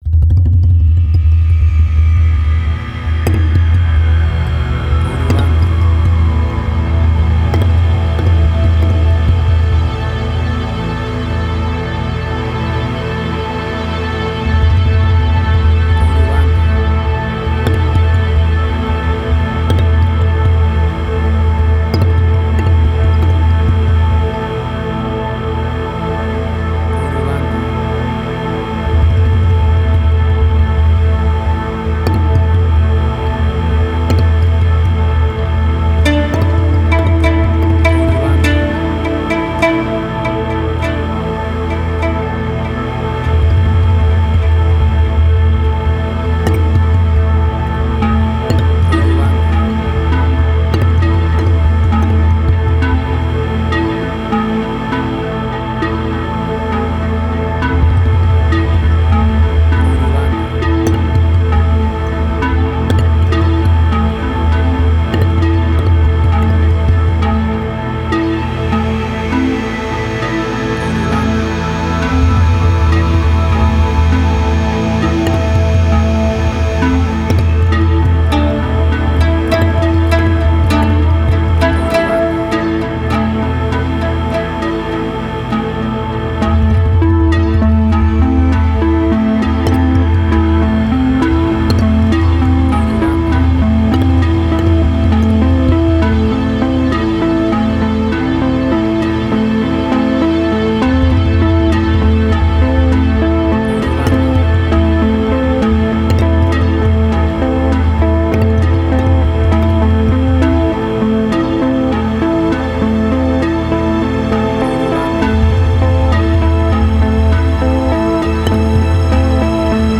Middle Eastern Fusion.
Tempo (BPM): 99